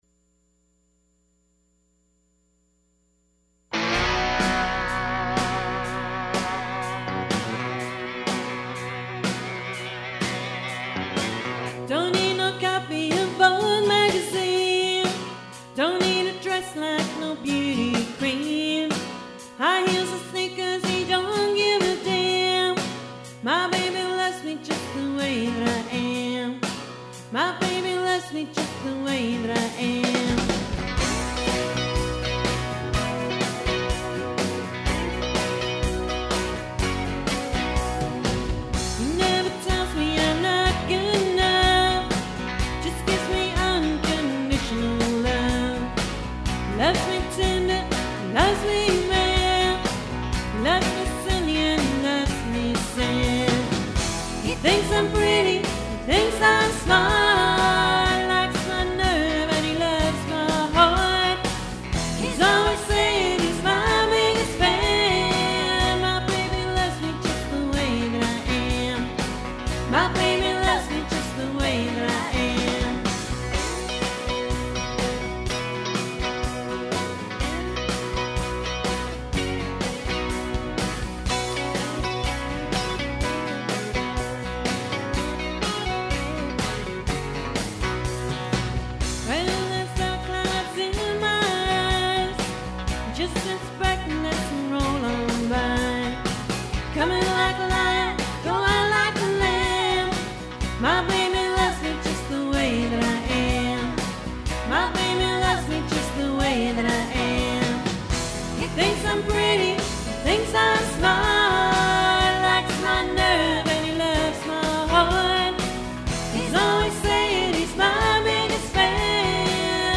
recorded at The Ryman Auditorium, Nashville, September 2008.